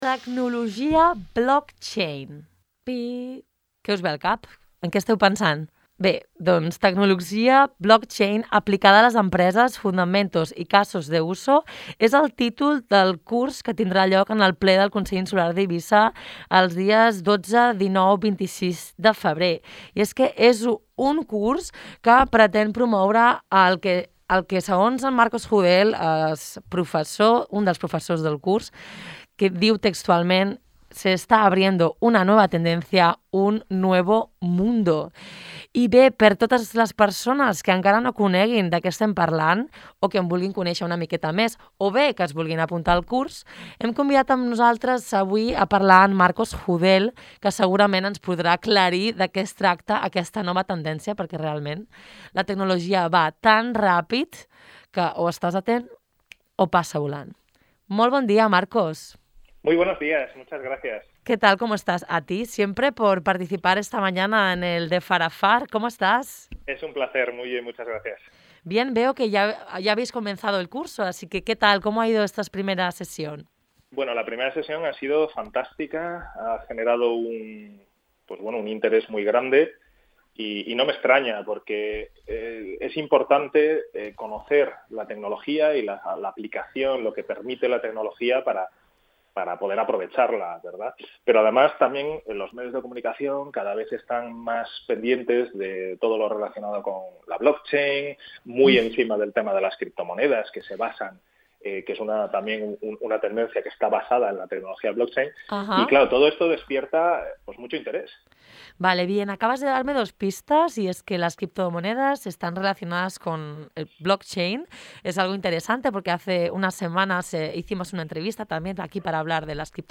En què consisteix i com s’aplica aquesta innovació? Consulta l’entrevista completa a continuació.